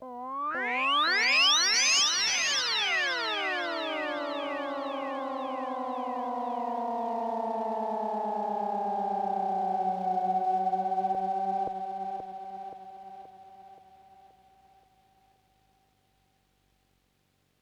Angry Cat 1.wav